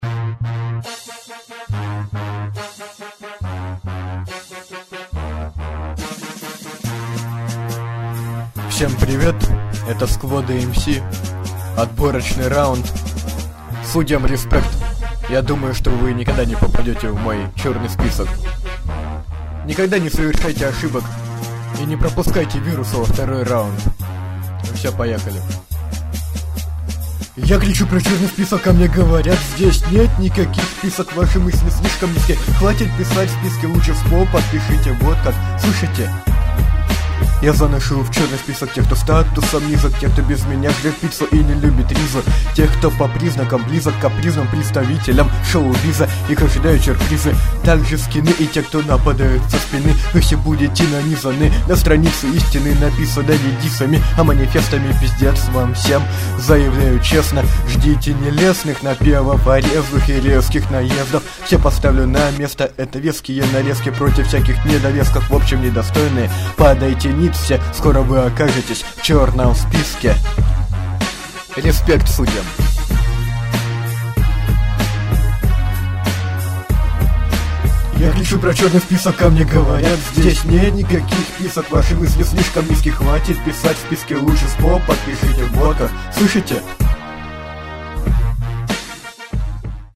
mp3,1405k] Рэп